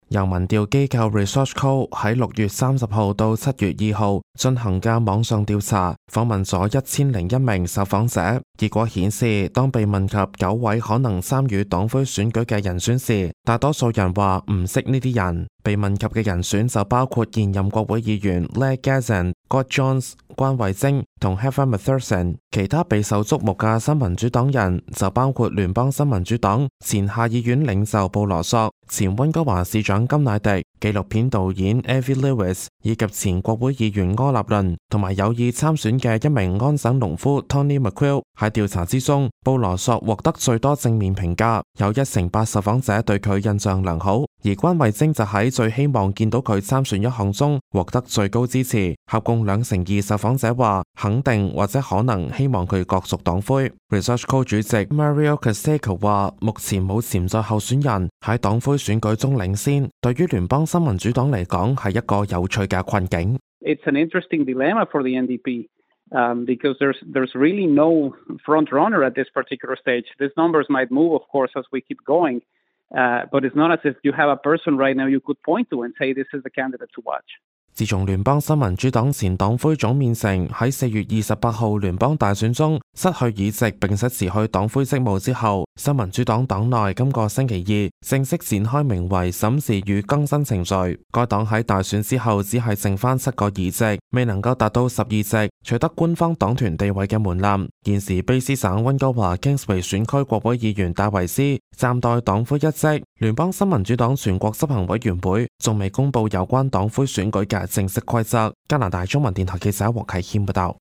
報道
news_clip_23885.mp3